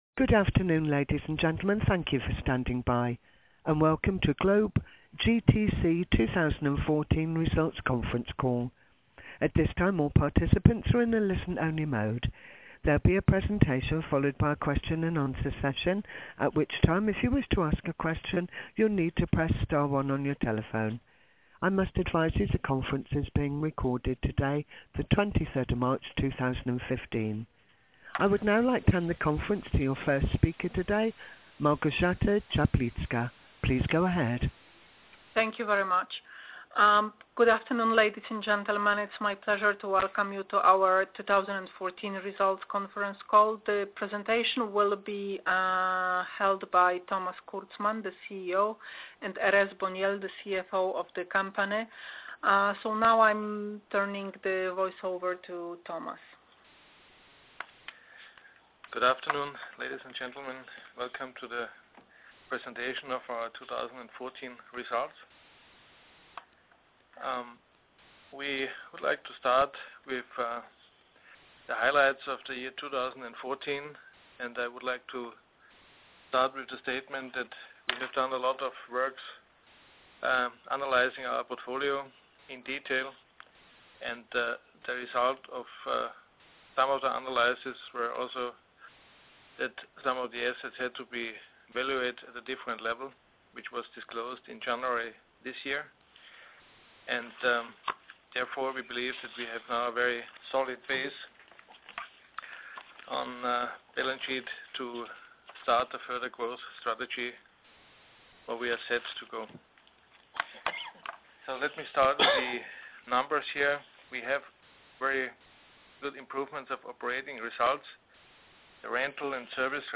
Wyniki za okres 12 miesięcy zakończony 31 grudnia 2014 r. (telekonferencja w języku angielskim)